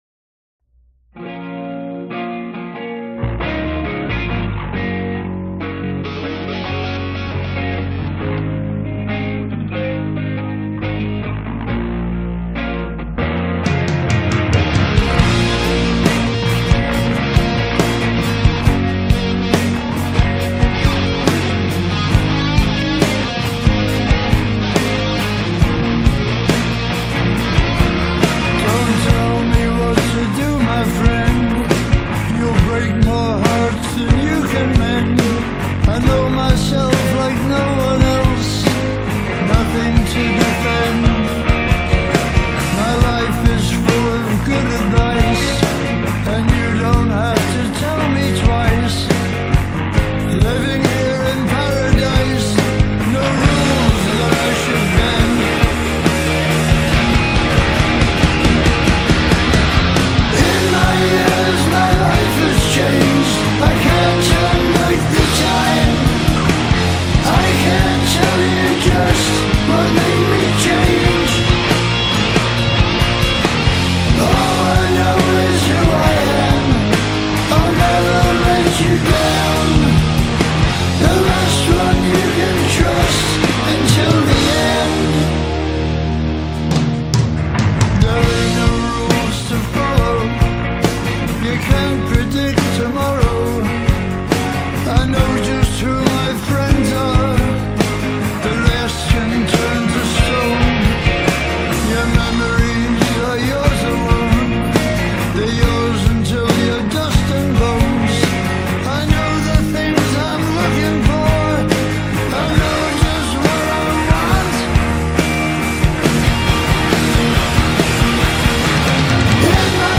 Metal
متال راک